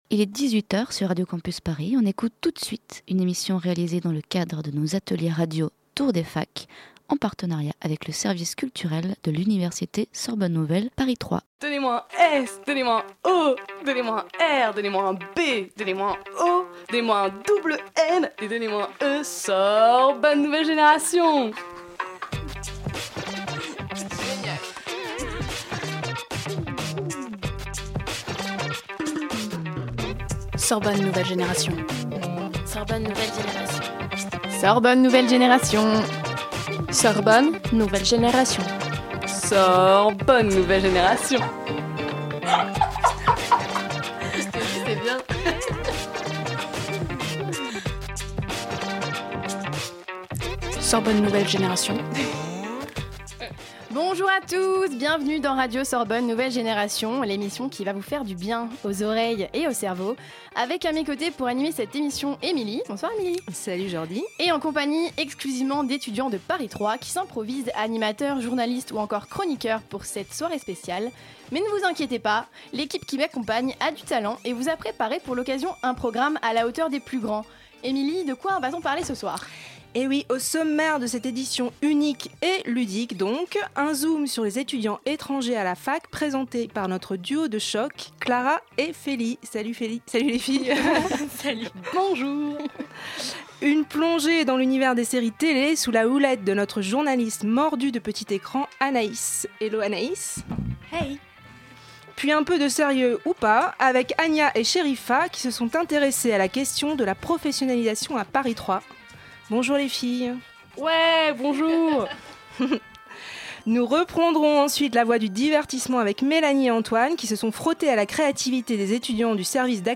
Après un cycle de 10 ateliers de formation intensive aux techniques radiophoniques, les 9 étudiants participants ont enregistré une émission de radio dans les conditions du direct dans notre studio.